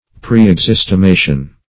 Search Result for " preexistimation" : The Collaborative International Dictionary of English v.0.48: Preexistimation \Pre`["e]x*is`ti*ma"tion\, n. Previous esteem or estimation.